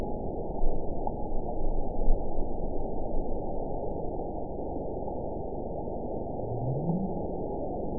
event 912348 date 03/25/22 time 07:45:34 GMT (3 years, 1 month ago) score 9.68 location TSS-AB03 detected by nrw target species NRW annotations +NRW Spectrogram: Frequency (kHz) vs. Time (s) audio not available .wav